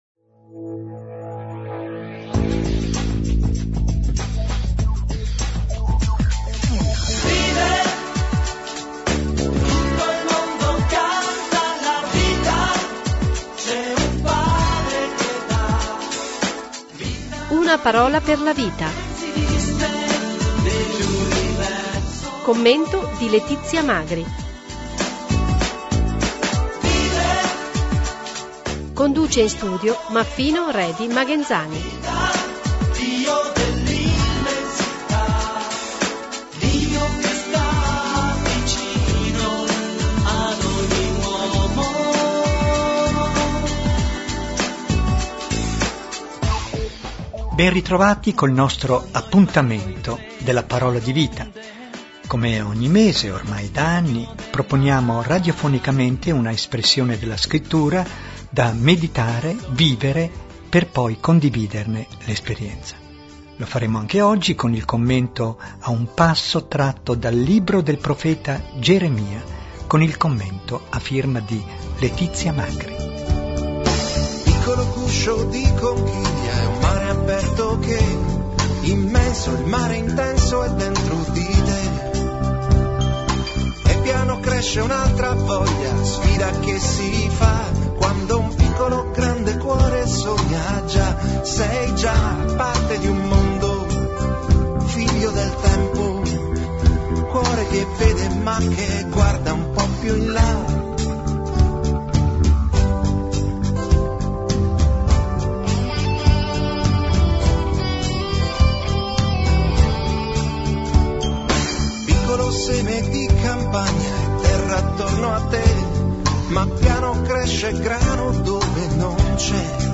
Conduce in studio